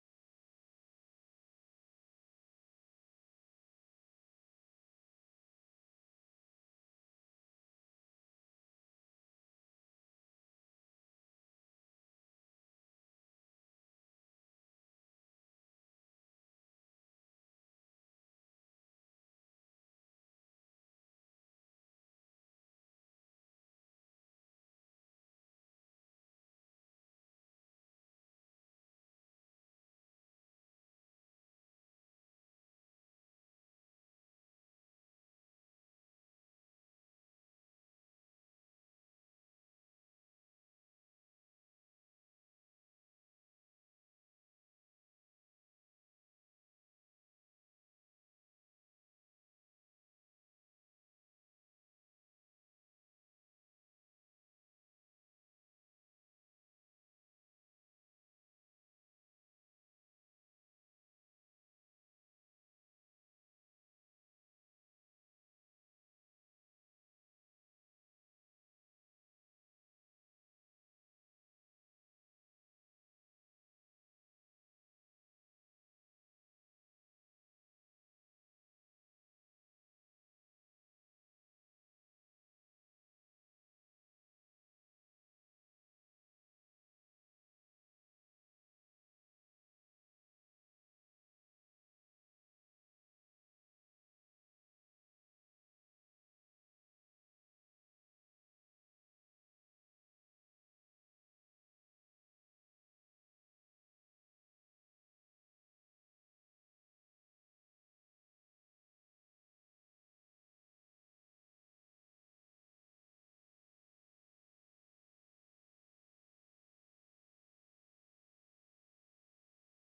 Waging Peace part 2 Sermon